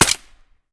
shoot_sil.ogg